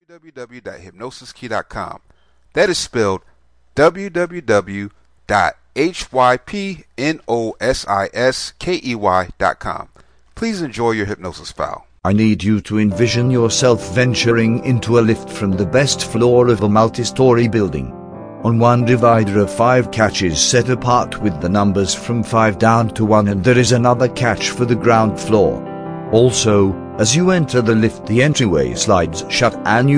This hypnosis script will make you feel like a puppet who is lying down, thats how relaxed you will feel. Hypnosis is theorized to work by altering your state of mind, it does this in such a way that the left brain is turned off, while the non-analytical right side is made alert.